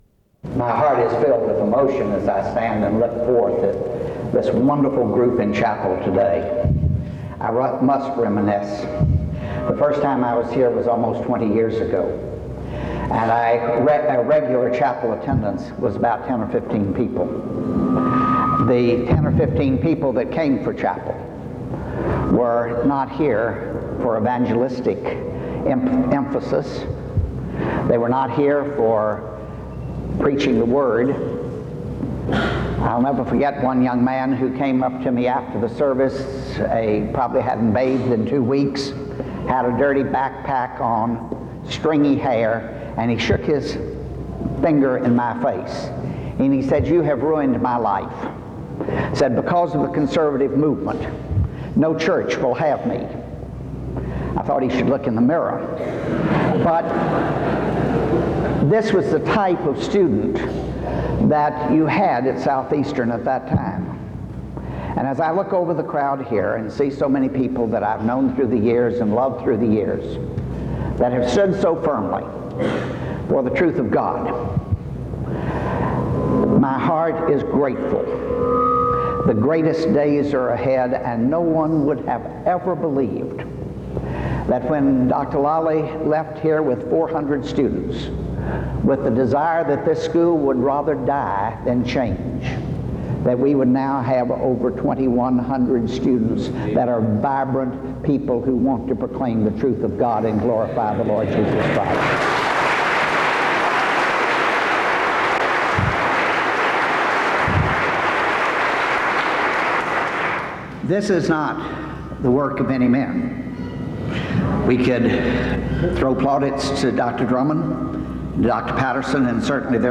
File Set | SEBTS_Chapel_Paul_Pressler_2000-09-14.wav | ID: 845cd748-a6ac-4303-8ce9-adc977b68f3f | Hyrax